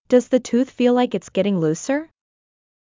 ﾀﾞｽﾞ ｻﾞ ﾄｩｰｽ ﾌｨｰﾙ ﾗｲｸ ｲｯﾂ ｹﾞｯﾃｨﾝｸﾞ ﾙｰｻｰ